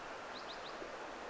tällainen äänityyppi, kolmiosainen,
on nyt ilmaantunut talitiaisille
kun ne vain harvakseen tuolla tapaa ääntelevät.
nyt_on_tullut_talitiaisia_jotka_ovat_mieltyneet_taman_tyyppisiin_kolmiosaisiin_aantelyihin.mp3